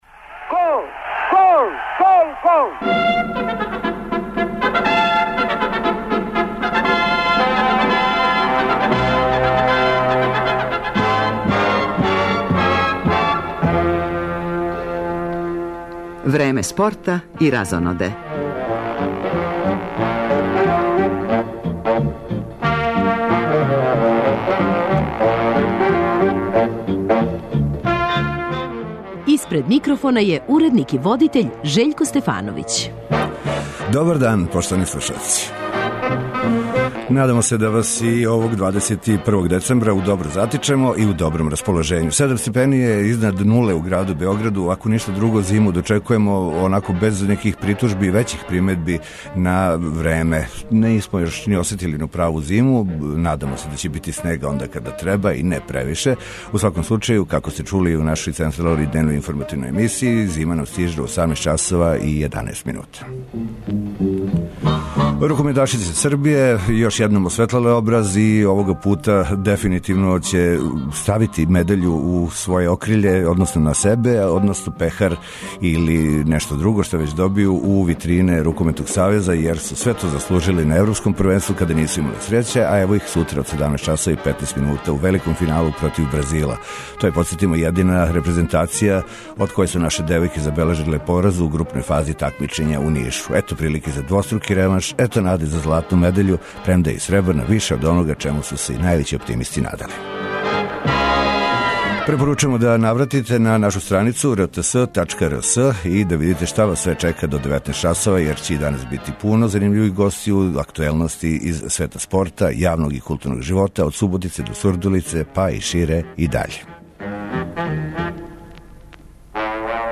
Момчило Бајагић увелико се припрема за концертне спектакле у Новом Саду и Будви,они су повод његов доласка у наш студио, али и иницијална каписла за многе занимљиве приче.